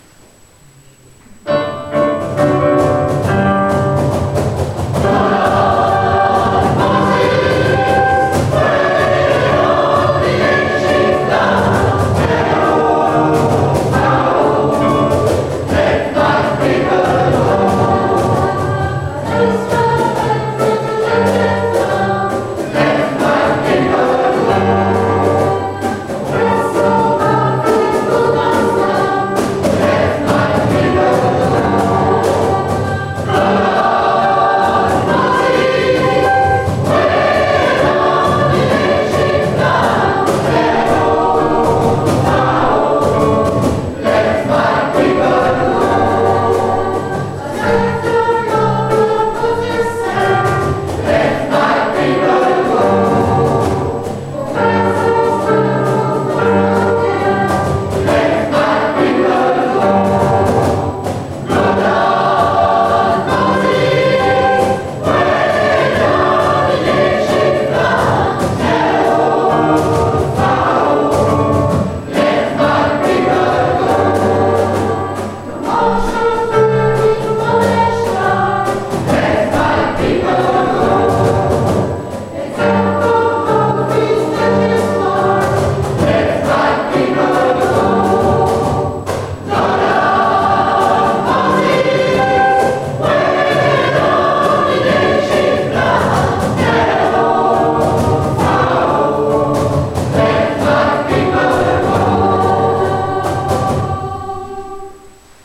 Pour ceux qui vous sont proposés à l'écoute, vous voudrez bien excuser les parasites et la qualité inégale des enregistrements, tous réalisés en public.
Go down, Moses (2.36 Mo) Gospel américain USA XIXème